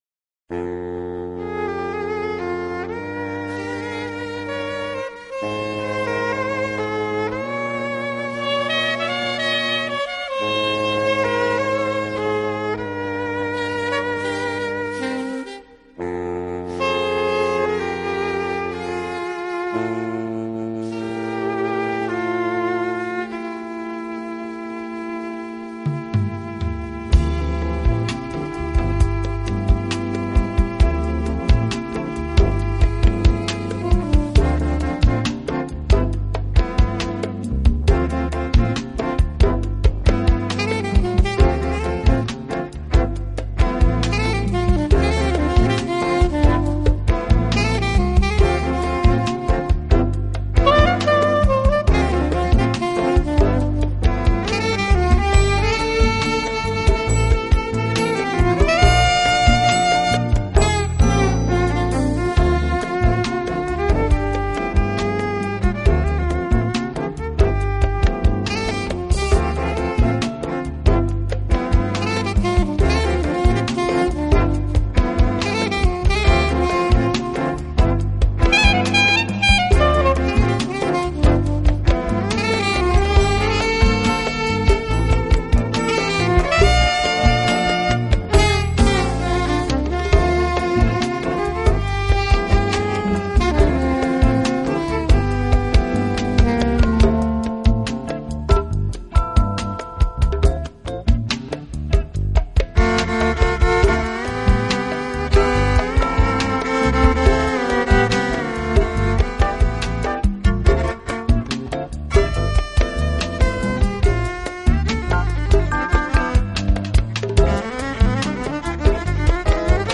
R&B、Pop，不但在商业上获得成功，同时更为日后的Smooth Jazz打下了基础。